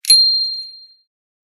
bicycle-bell_12
bell bicycle bike clang contact ding glock glockenspiel sound effect free sound royalty free Sound Effects